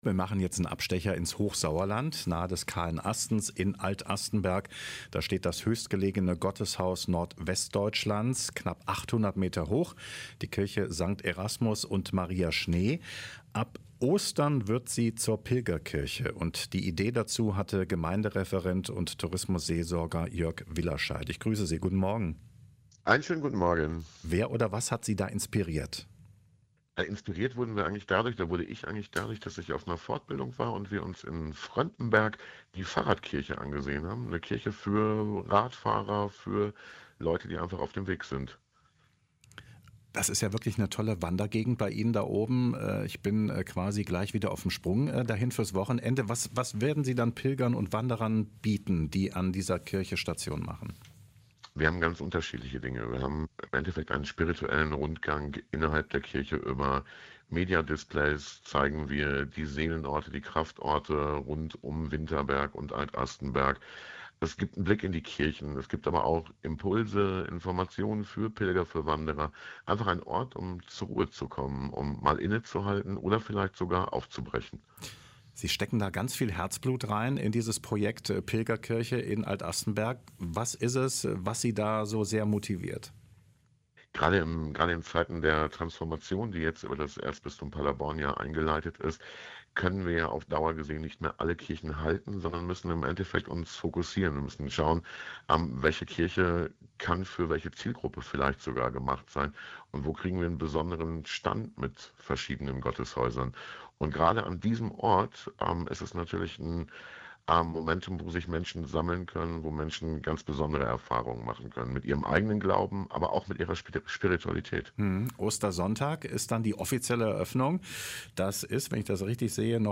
Interview über die höchste Pilgerkirche Nordwestdeutschlands